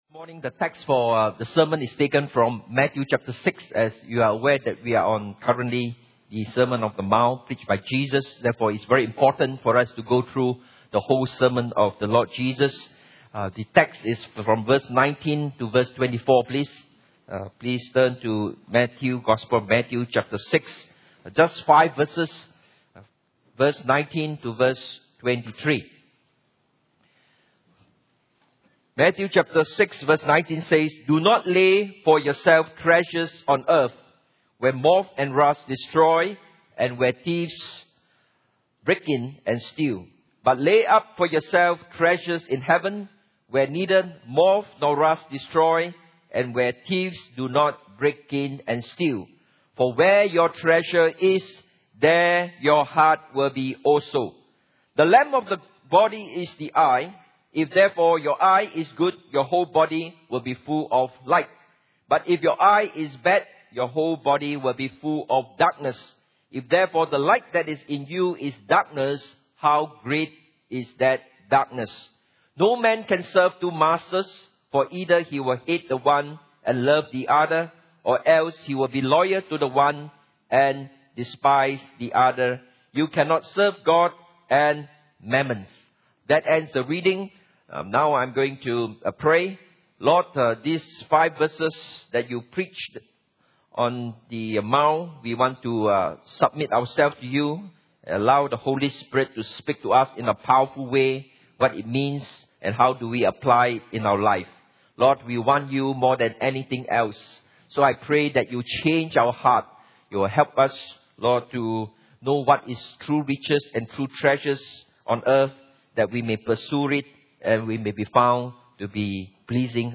Sermon
Service Type: Sunday Morning